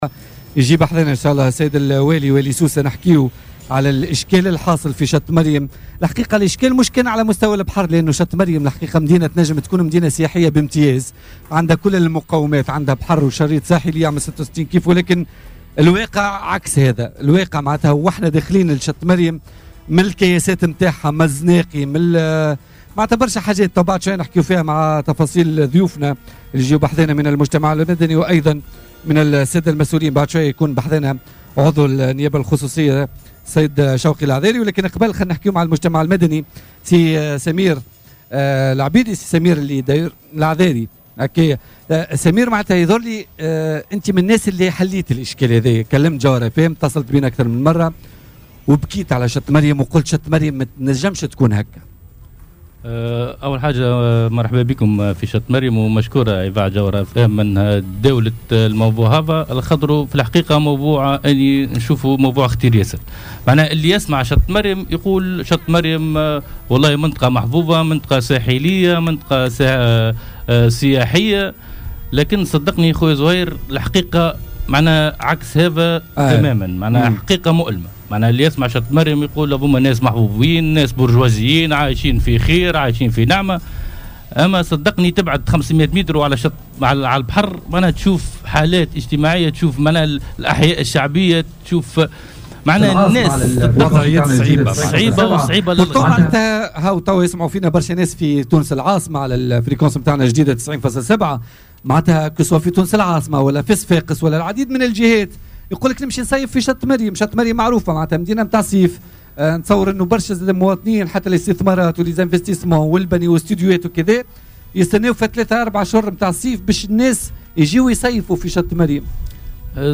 تنقّل فريق "بوليتيكا" اليوم الخميس إلى منطقة شط مريم بسوسة في بث مباشر من فضاء "تور ايفال" السياحي.